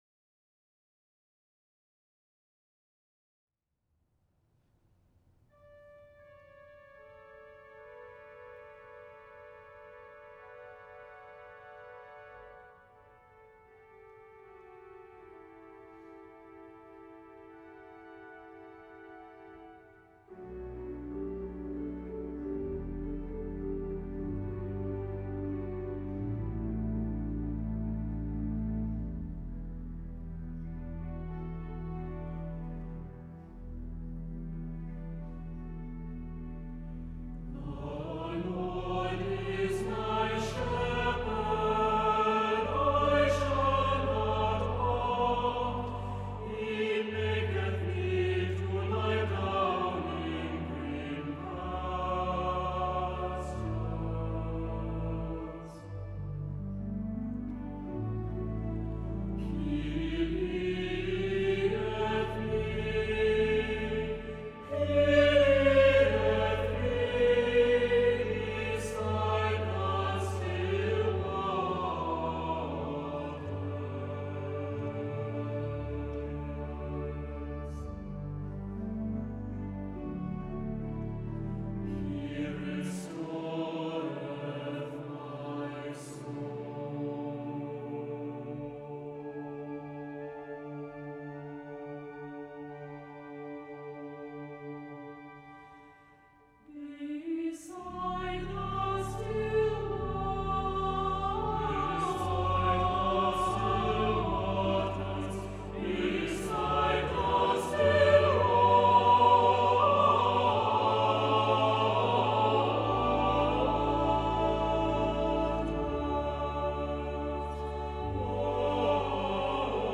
for SATB Chorus and Organ (1998)